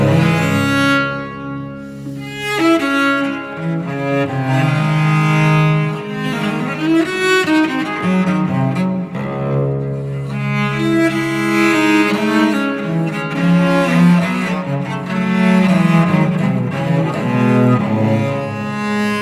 Audición de diferentes sonidos de la familia de cuerda frotada.
Viola de Gamba